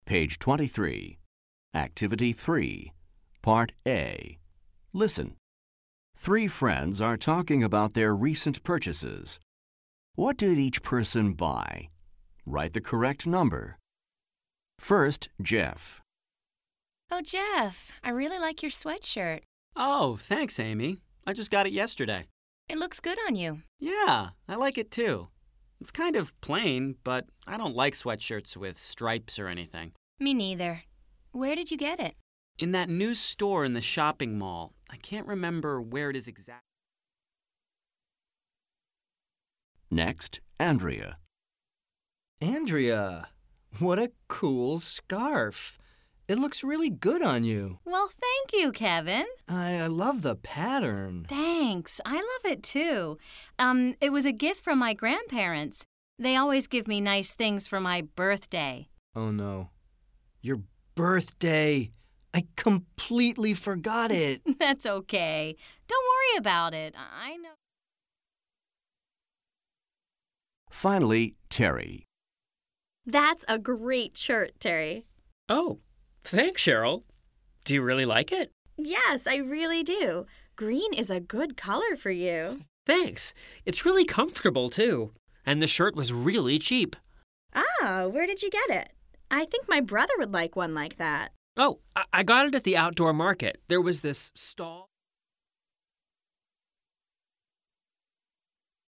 American English
New recordings offer authentic listening experiences in a variety of genres, including conversations, interviews, and radio and TV shows.